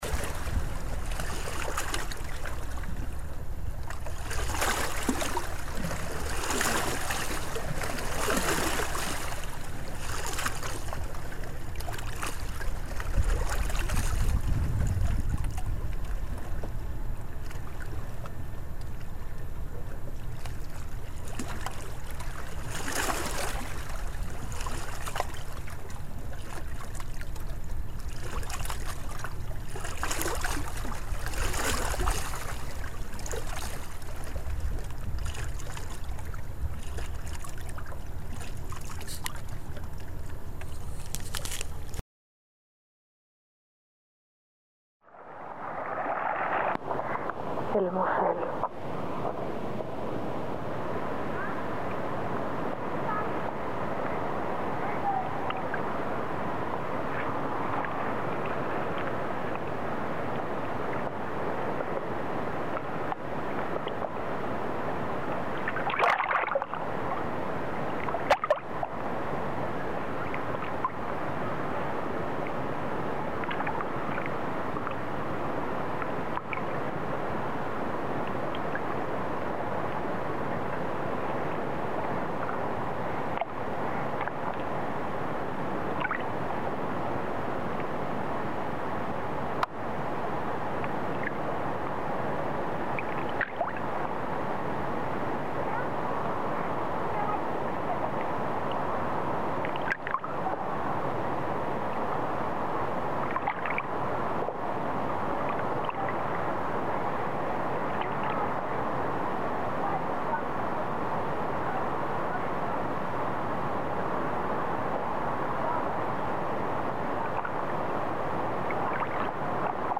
rivers
extracto del trenzado de las voces de los r�os